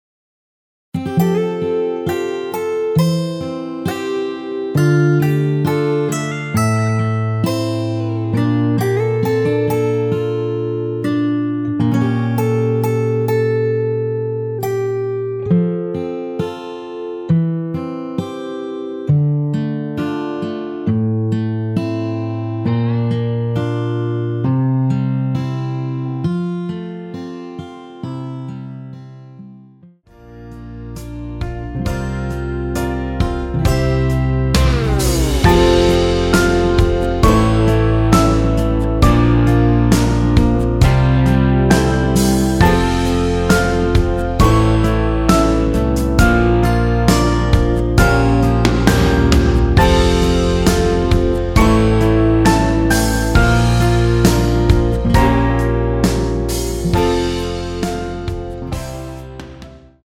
원키에서(+5) 올린 MR 입니다.
앞부분30초, 뒷부분30초씩 편집해서 올려 드리고 있습니다.